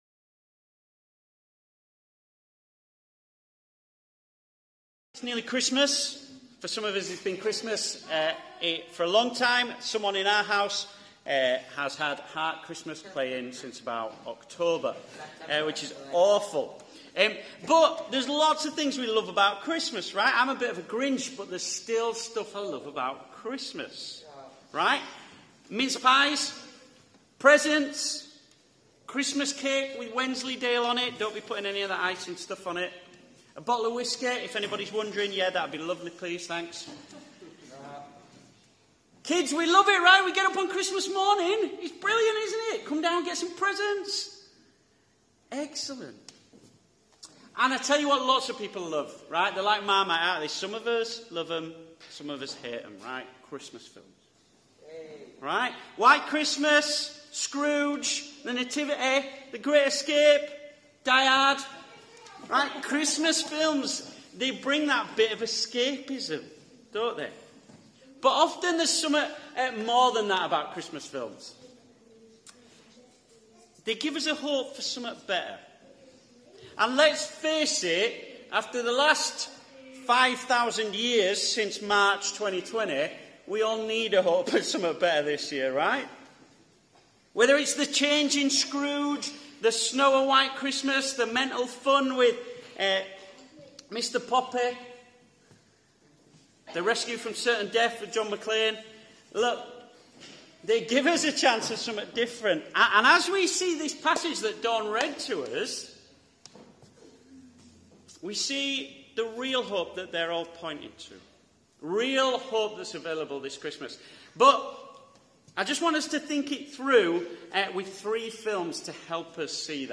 Carol Service: It’s a Wonderful Life